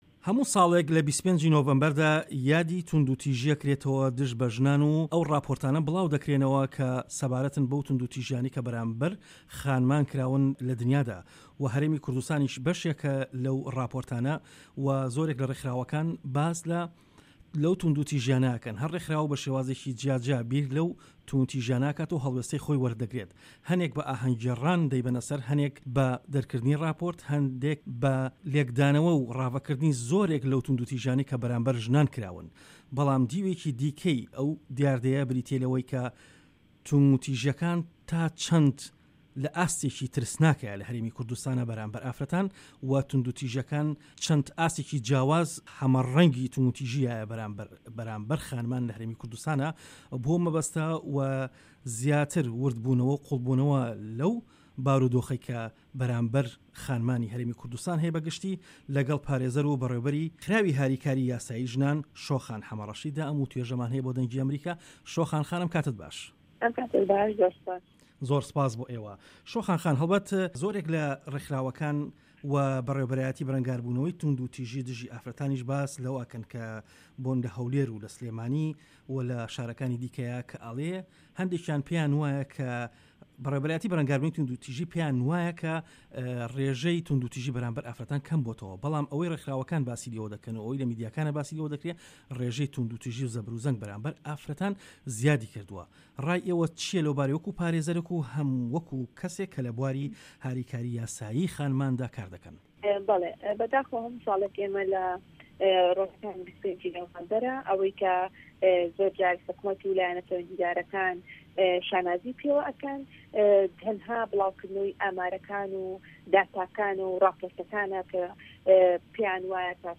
وتووێژ